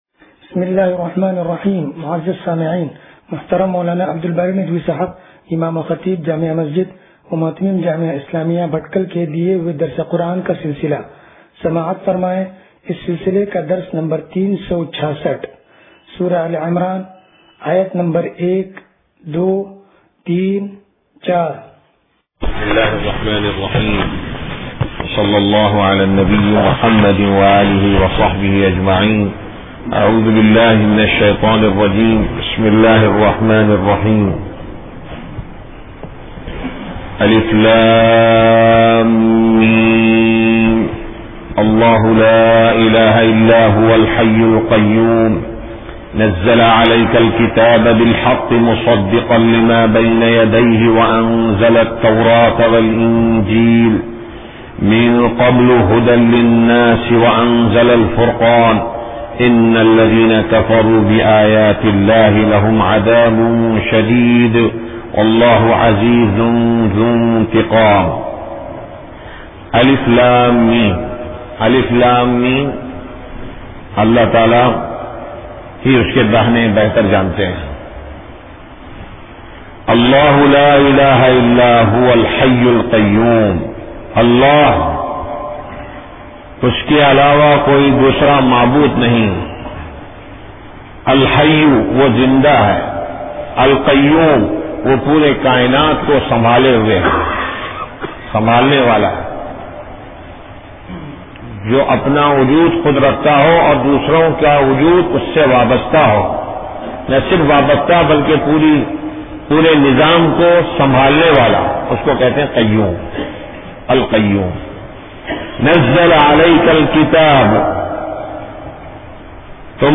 درس قرآن نمبر 0366